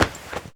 foley_combat_fight_grab_throw_05.wav